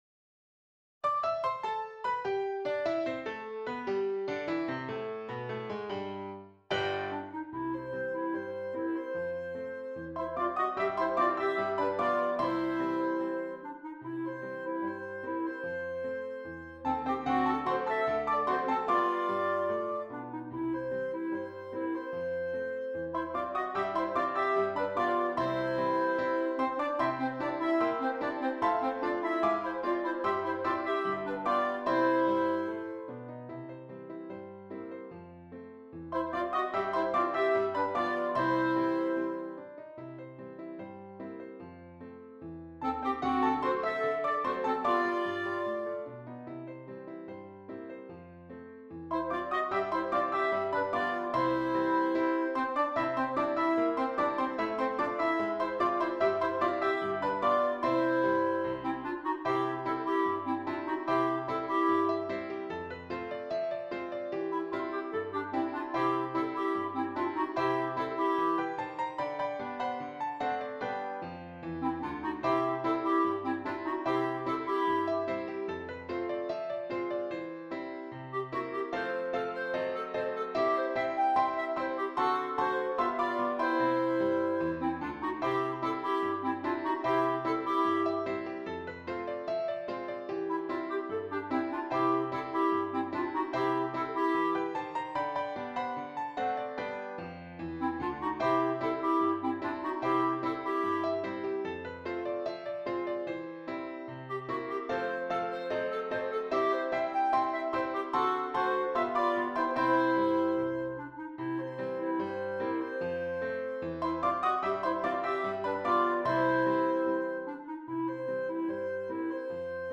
2 Clarinets and Keyboard